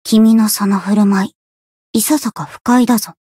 灵魂潮汐-青黛-互动-不耐烦的反馈1.ogg